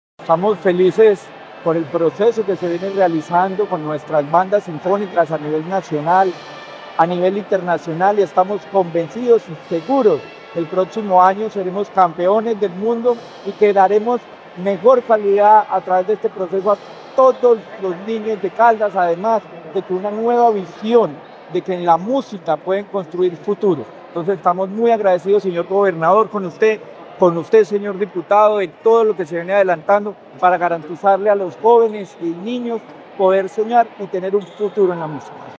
Secretario de Educación de Caldas, Luis Herney Vargas Barrera.